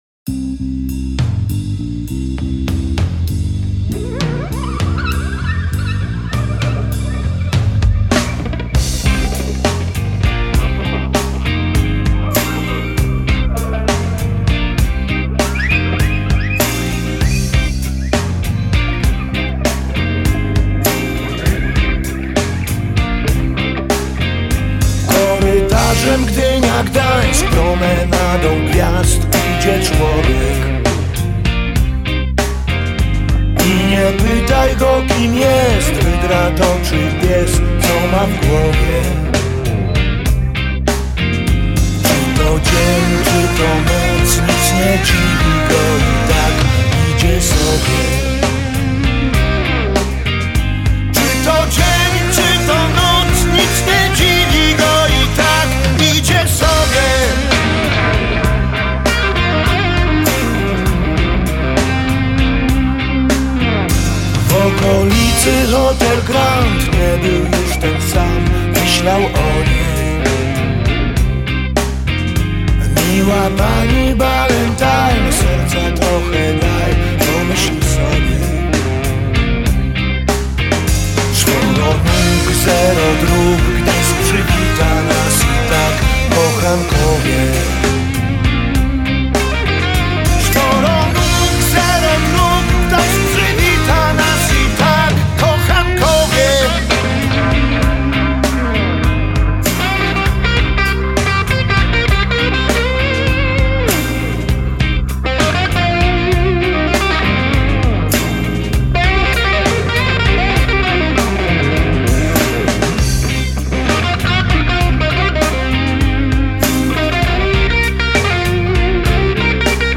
popular Polish rock band